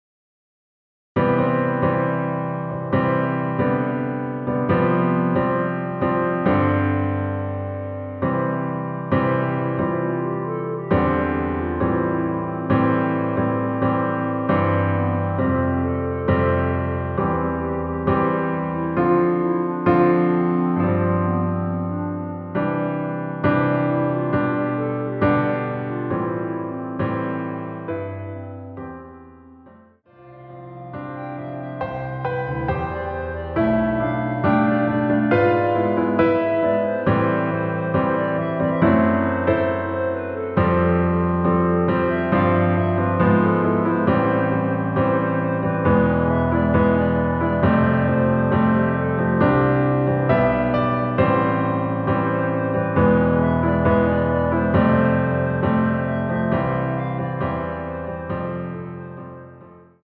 반주를 피아노 하나로 편곡하여 제작하였습니다.
원키에서(-1)내린(Piano Ver.) (1절+후렴)멜로디 포함된 MR입니다.